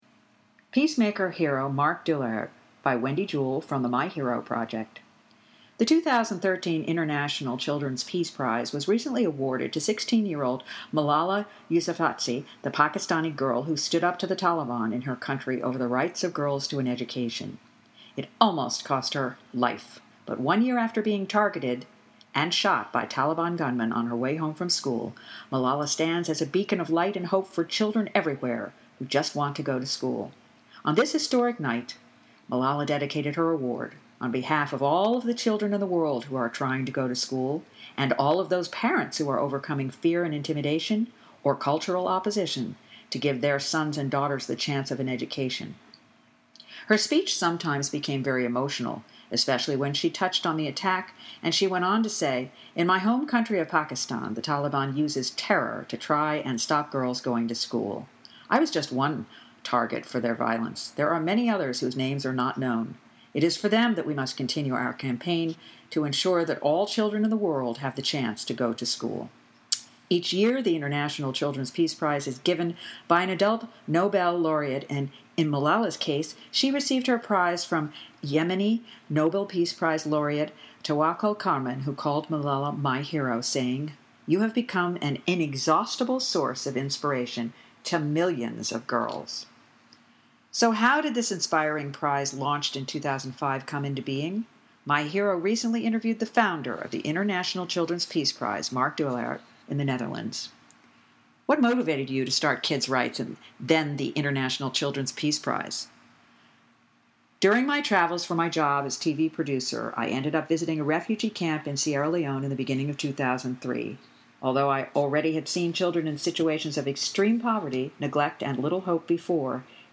Stories